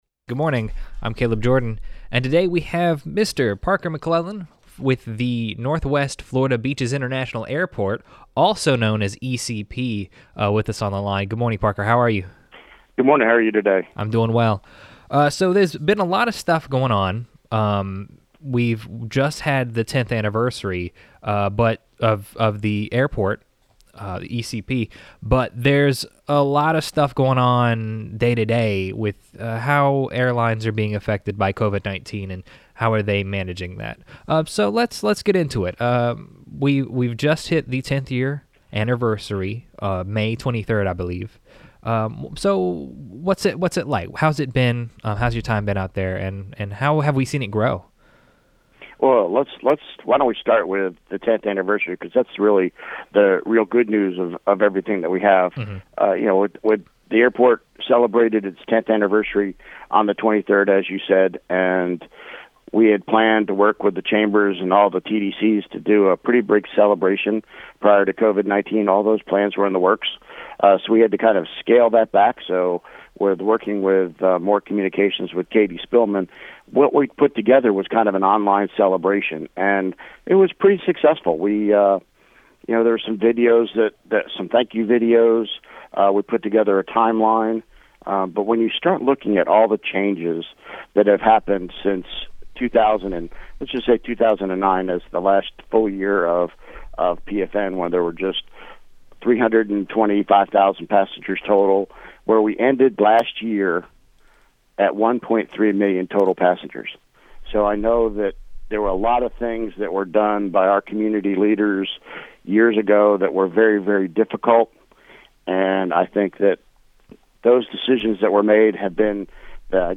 Morning-Mix-Interview-ECP-06-03-20.mp3